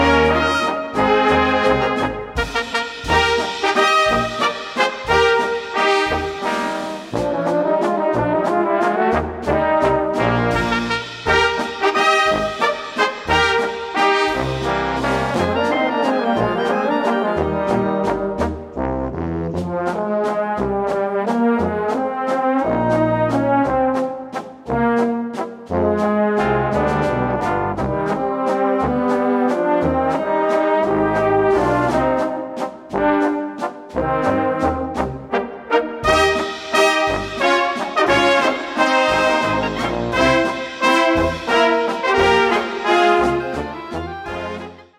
Gattung: Walzer für Blasorchester
Besetzung: Blasorchester